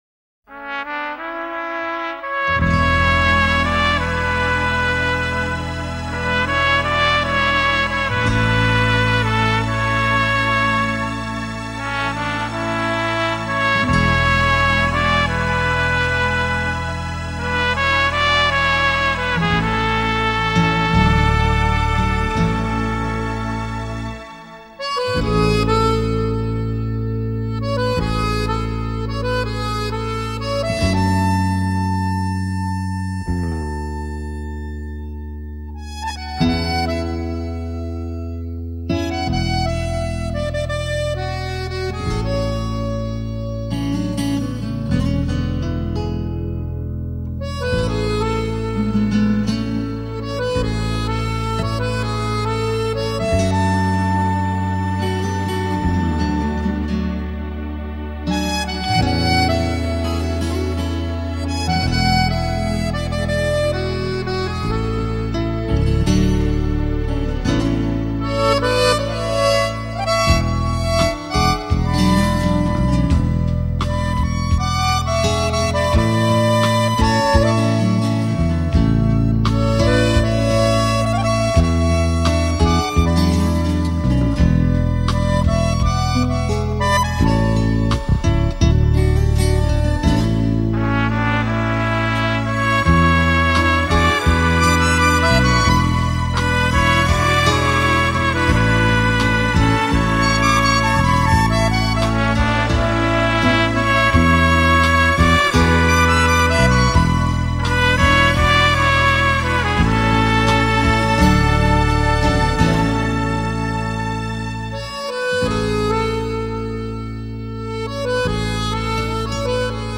手风琴总给人一种历经沧桑后沉静的感觉
手风琴清淡的抒情 将听者卷入缠绵缱绻又清亮淡静的真情享受